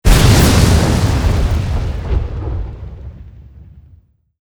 academic_newskill_magmawave_02_explosion.ogg